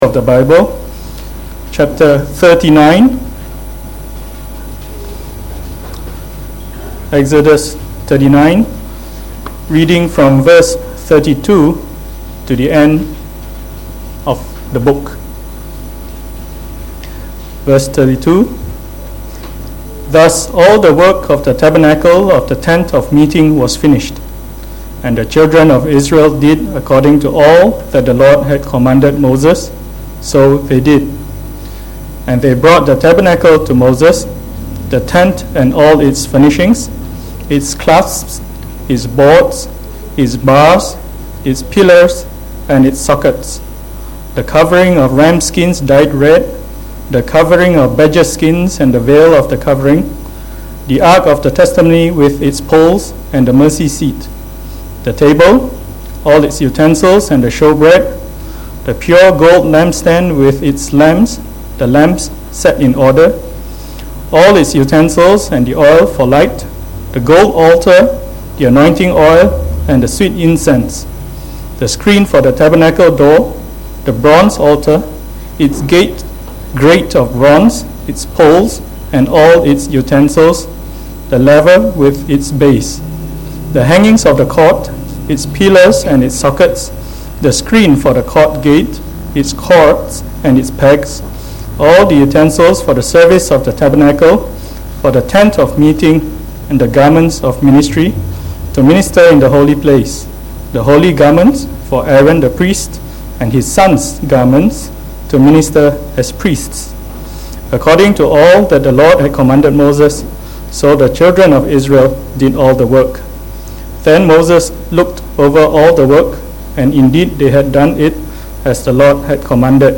delivered in the Morning Service